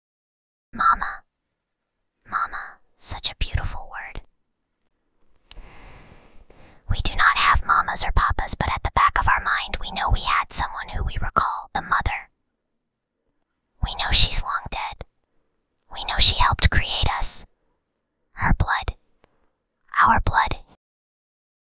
Whispering_Girl_10.mp3